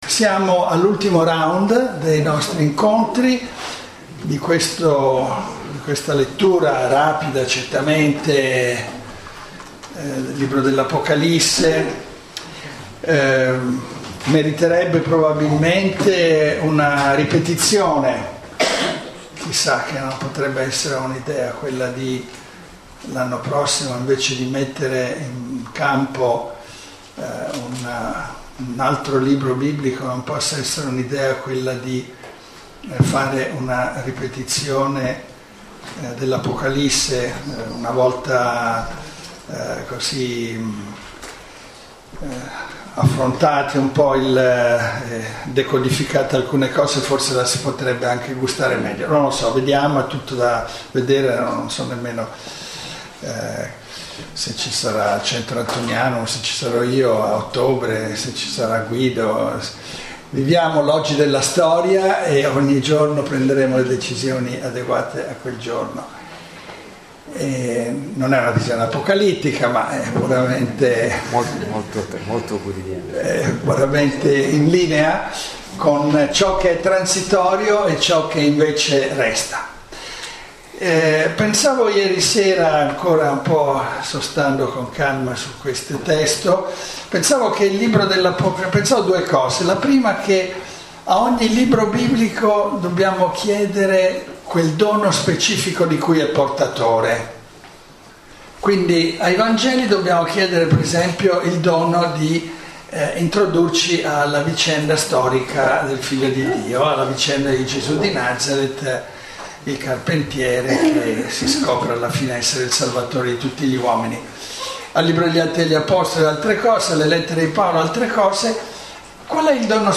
Lectio 7 – 13 aprile 2014 – Antonianum – Padova